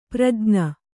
♪ prajña